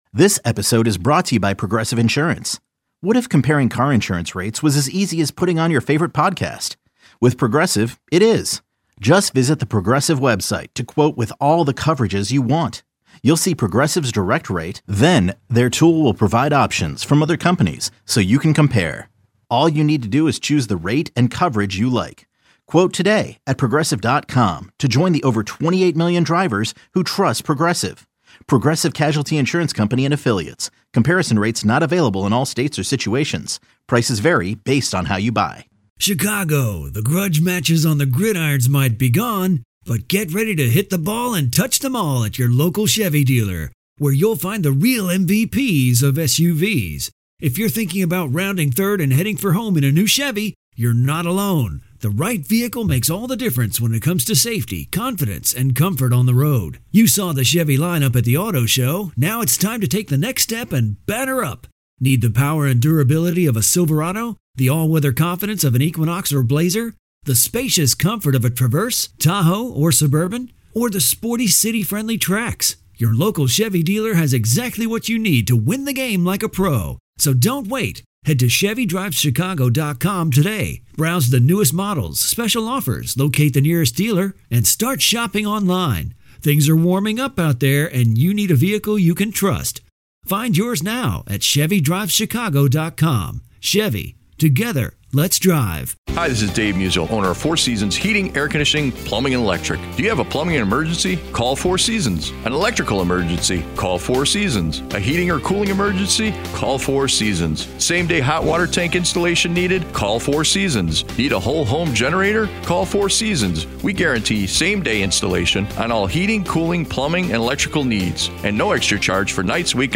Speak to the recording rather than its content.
live weekdays 2-4PM